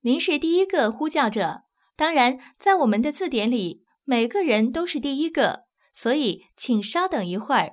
ivr-youre_number_one.wav